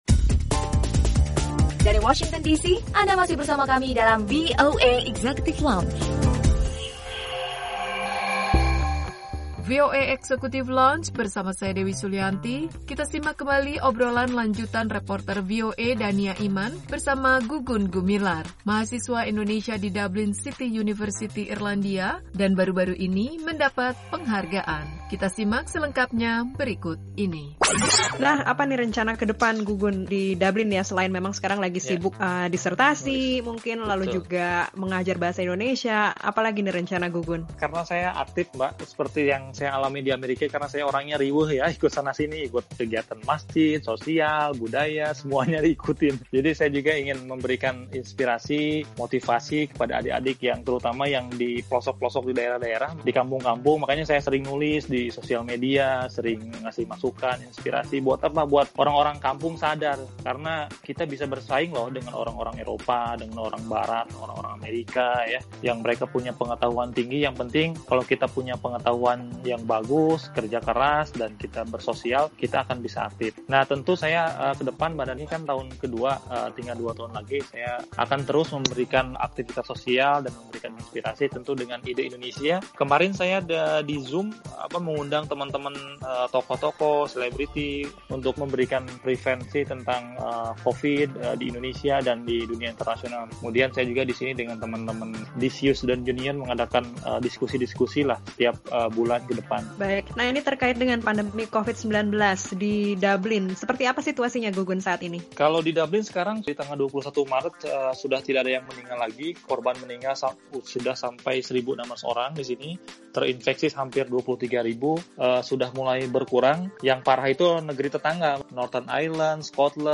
Obrolan lanjutan